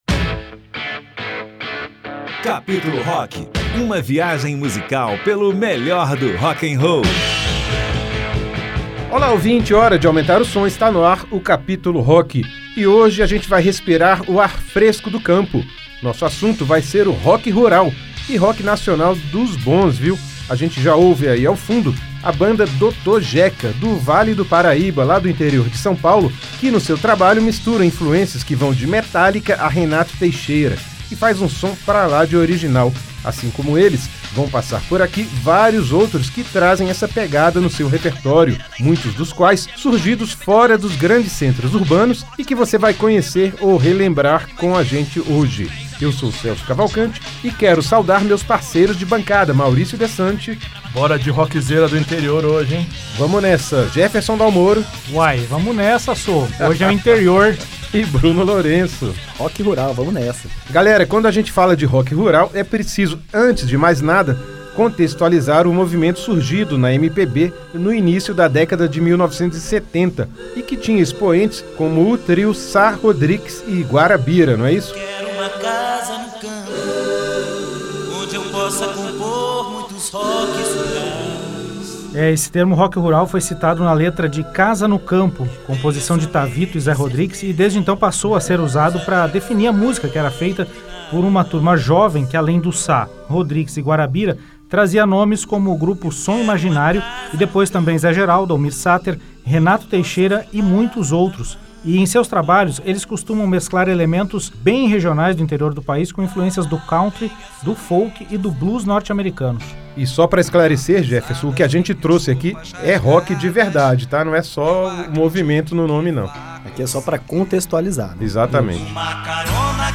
O rock rural feito no Brasil é o destaque do Capítulo Rock desta semana. O programa reapresenta uma seleção de bandas e artistas que trazem essa temática em seu repertório, e mesclam elementos da música regional brasileira ao country, o folk e o blues norte-americanos.